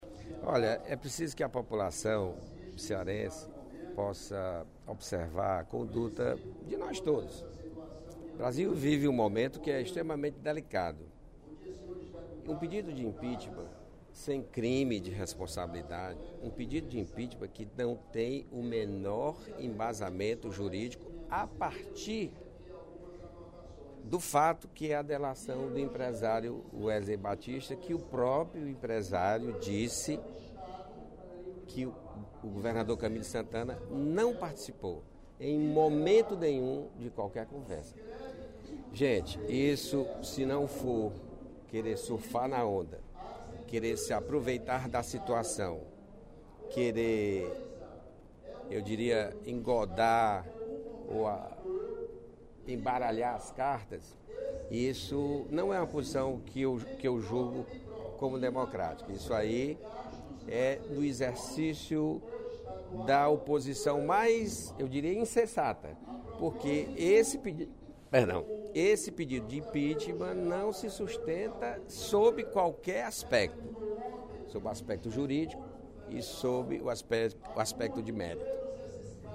O deputado José Sarto (PDT) avaliou, no primeiro expediente da sessão plenária desta terça-feira (23/05), que não há razão para o impeachment do governador Camilo Santana com a alegação de crime de responsabilidade.